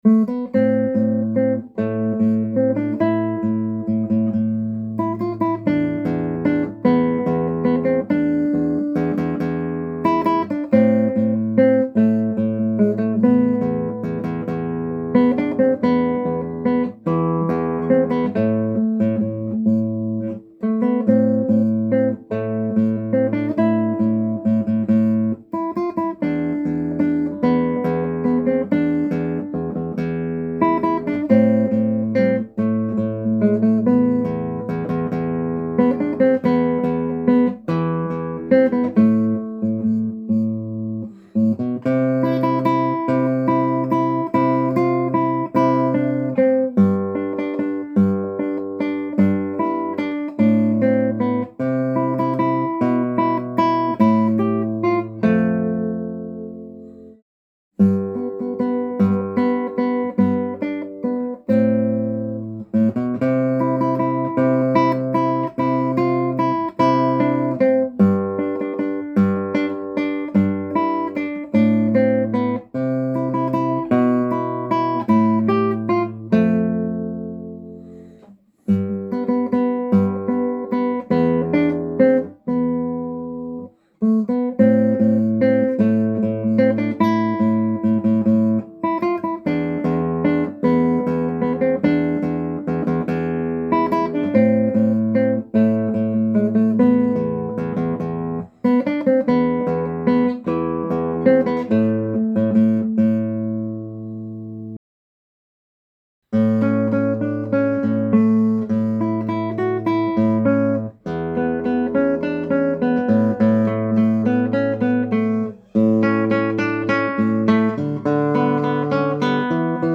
b) Easy-intermediate